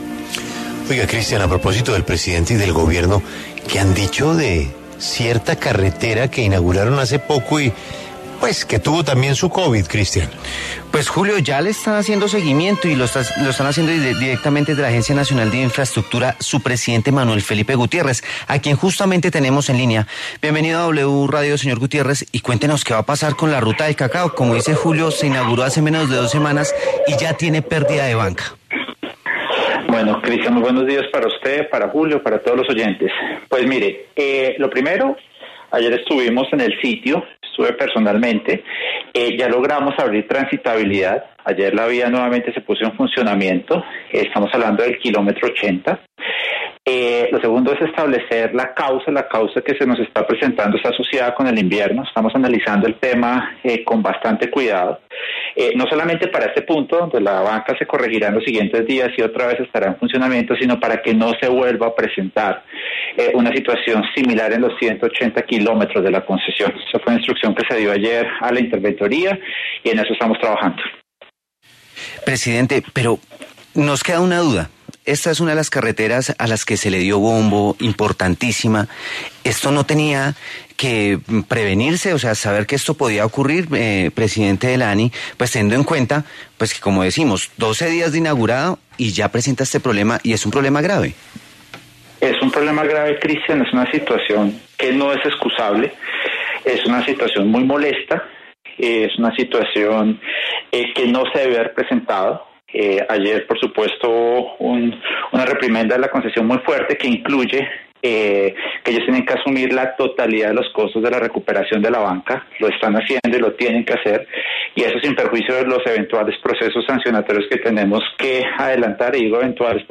En entrevista con La W, el presidente de la Agencia Nacional de Infraestructura (ANI), Manuel Felipe Gutiérrez, se pronunció sobre la emergencia que se registra en la Ruta del Cacao.